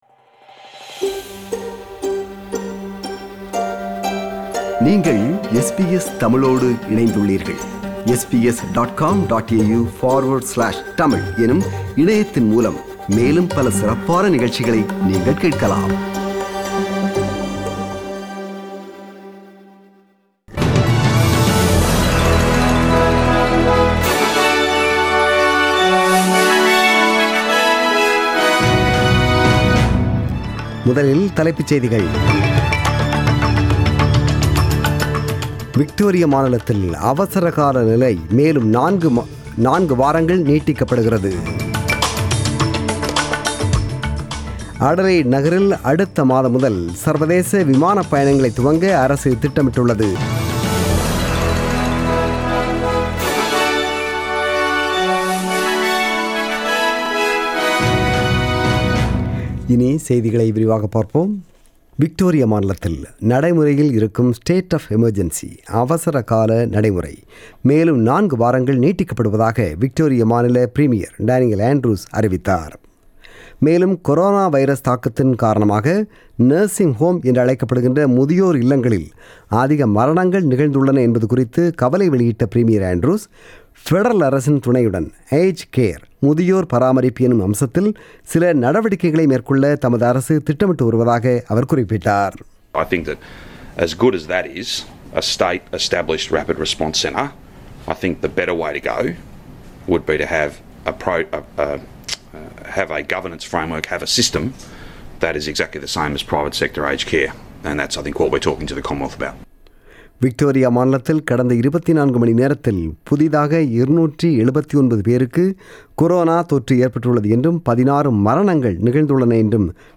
The news bulletin was broadcasted on 16 August 2020 (Sunday) at 8pm.